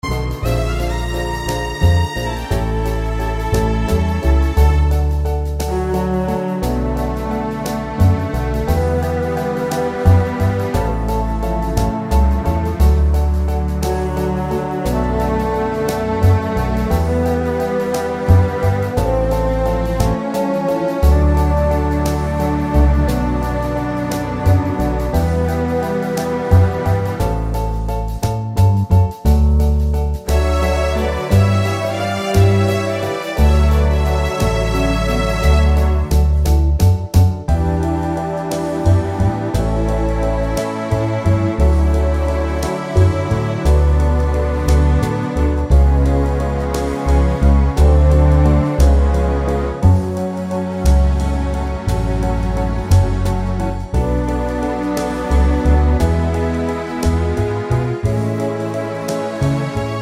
Key of D Jazz / Swing 2:59 Buy £1.50